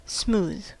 Ääntäminen
Vaihtoehtoiset kirjoitusmuodot smoothe (murteellinen) smeeth Synonyymit even suave unwrinkled shine flat bald polish unlined wrinkle-free nonabrasive wrinkleless Ääntäminen : IPA : /smuːð/ US : IPA : [smuːð]